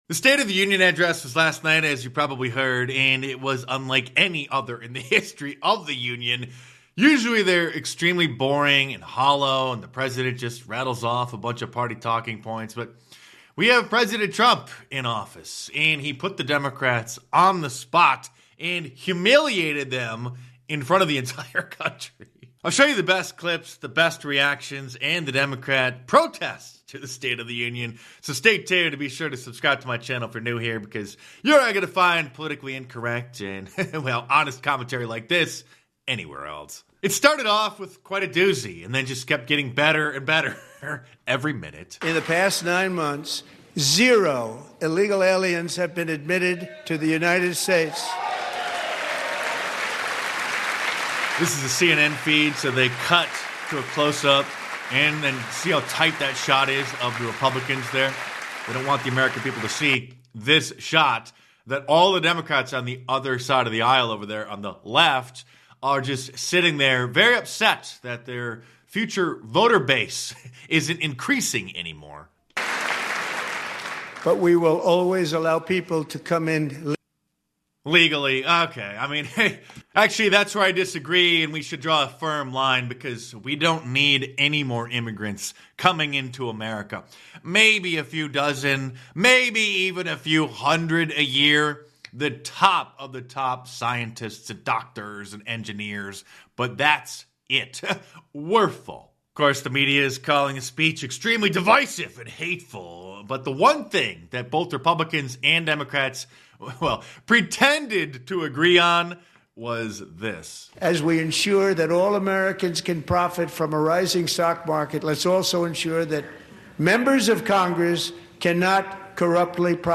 In a lively State of the Union address, President Trump tackled big topics like illegal immigration and voter ID laws. He criticized Democrats for their views and highlighted concerns about driver's licenses for illegal aliens.